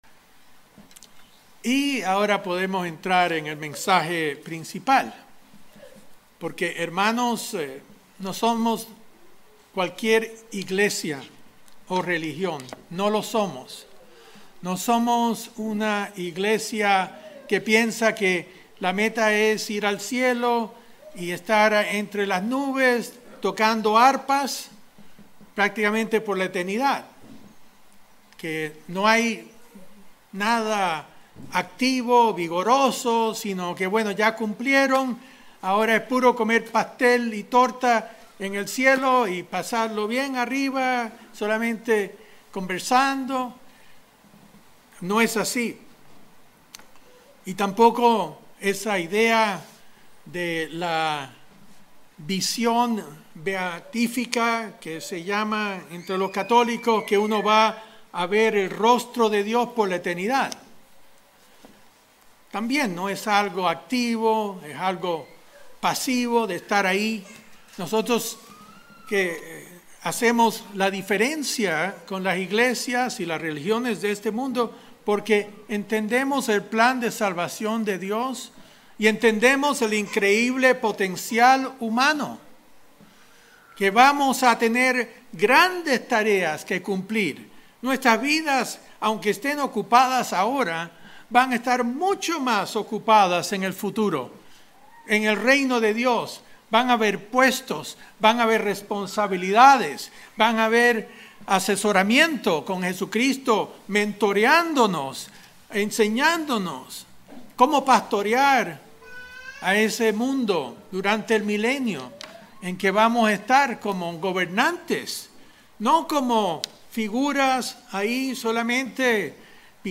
¿Somos realmente buenos pastores de nosotros mismos? Mensaje entregado el 2 de noviembre de 2021.